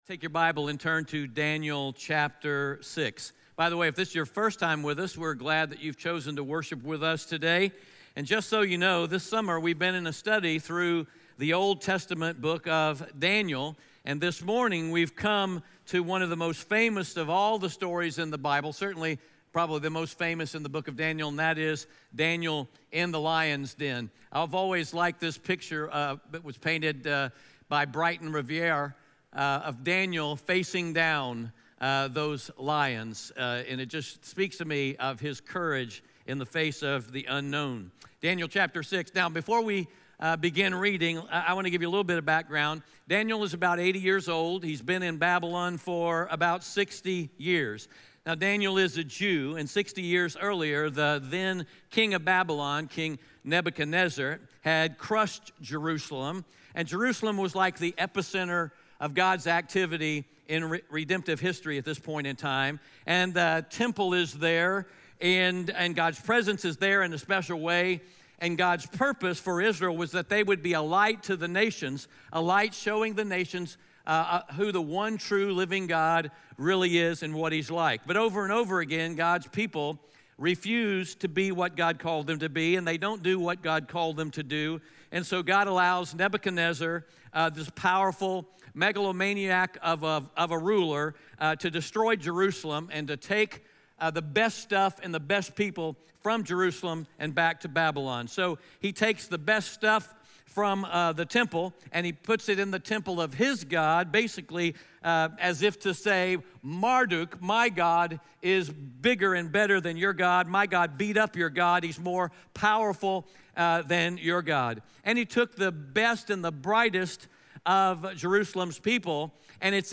Daniel 6 Audio Sermon Notes (PDF) Ask a Question PART 1 — Now before we start reading I want to give you a little bit of background.